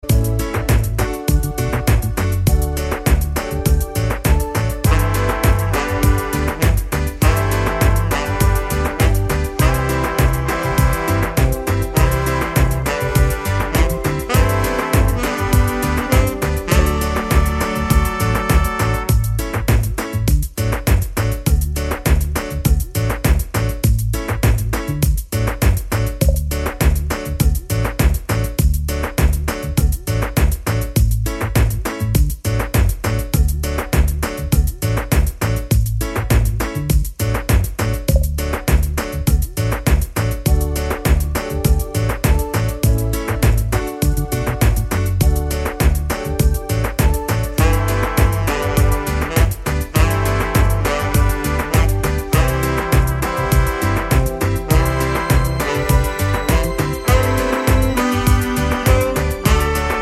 no Backing Vocals Reggae 4:21 Buy £1.50